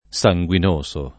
sanguinoso [ S a jgU in 1S o ] agg.